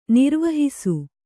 ♪ nirvahisu